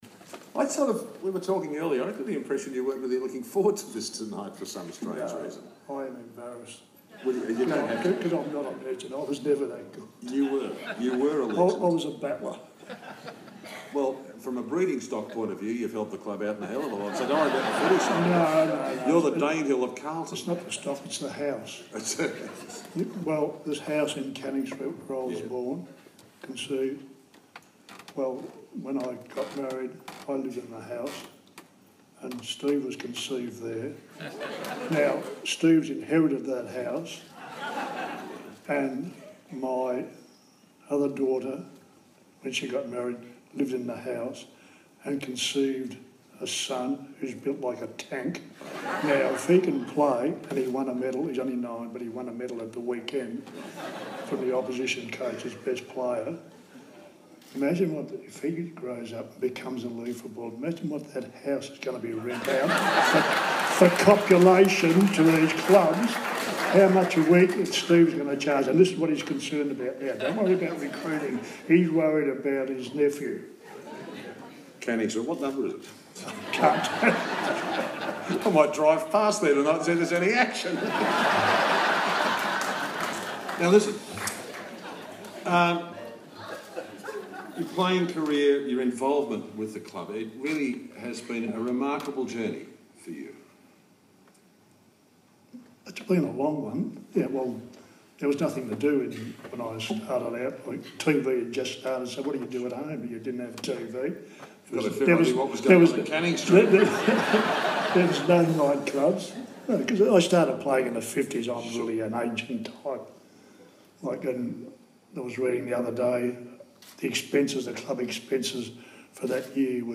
Sergio Silvagni - Hall of Fame speech
A pioneer of the game, Sergio ‘Serge' Silvagni was elevated to official Legend status at Carlton Football Club’s Hall of Fame event on April 29, 2016.